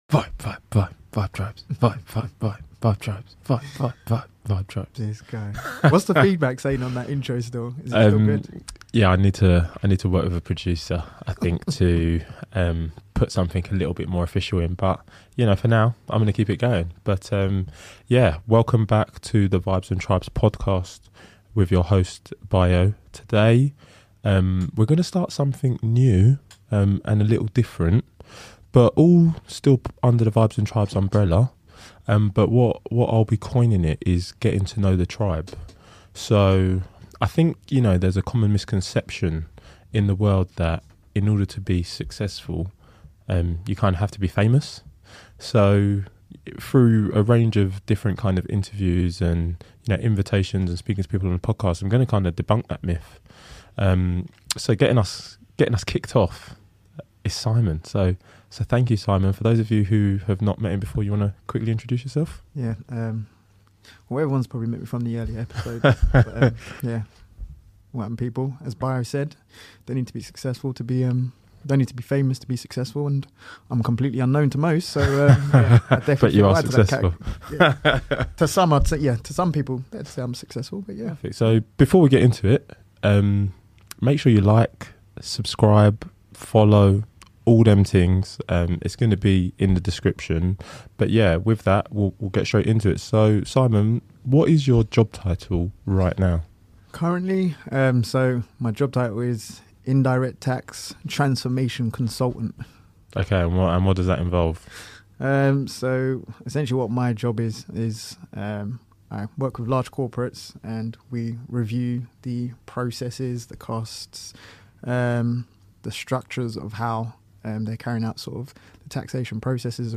Know the Tribe is a new segment where we will interview a range of guests, to understand their journeys through the working world.